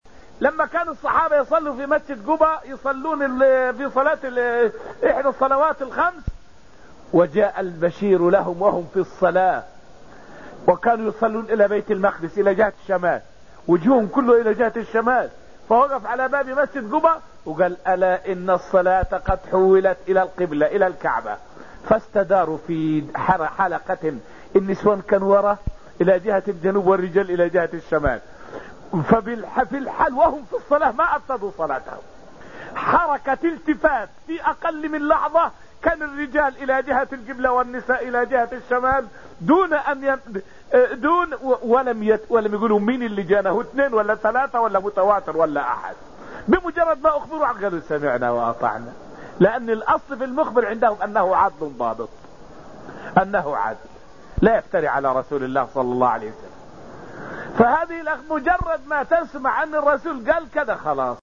فائدة من الدرس الحادي عشر من دروس تفسير سورة النجم والتي ألقيت في المسجد النبوي الشريف حول سرعة استجابة الصحابة رضوان الله تعالى عنهم لأمر الله تعالى وأمر رسوله صلى الله عليه وسلم.